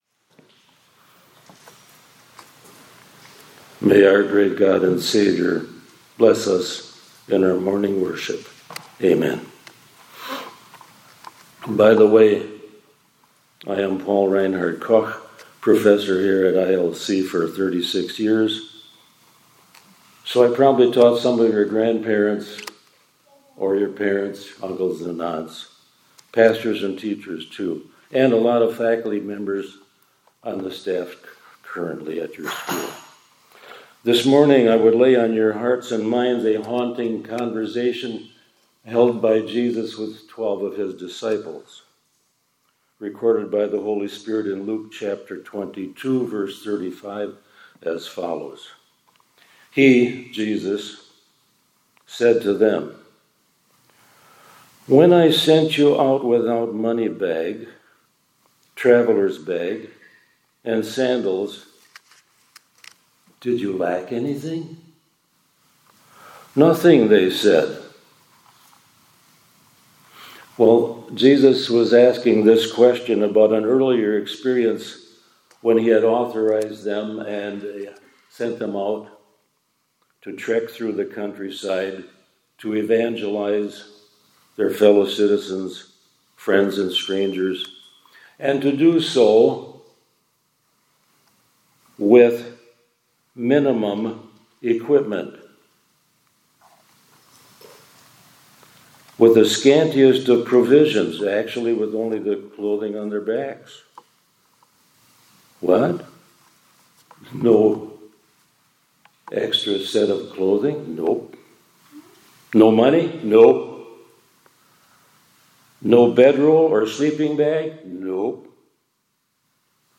2025-08-26 ILC Chapel — God’s Credit Card is Accepted…